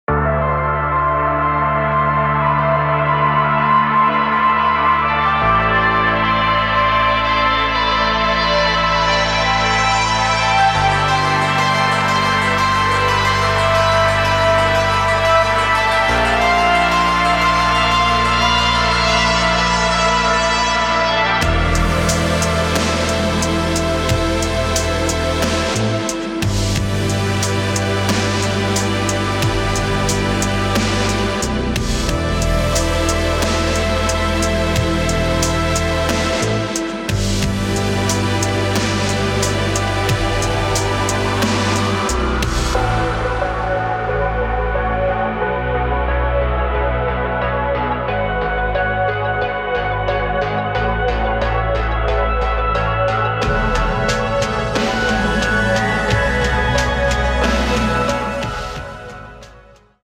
Synthwave
厚重的模拟低音、梦幻般的丰富垫音、耀眼的电影风格键盘以及复古风格的合成器主音。